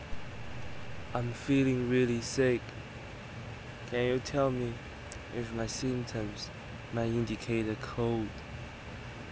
illness1_AirConditioner_2.wav